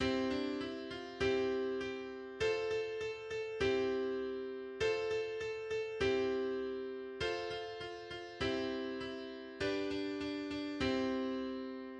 Kinderreim